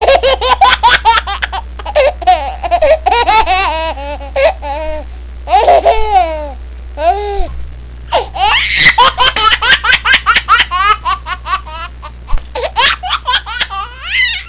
Ringetone Grine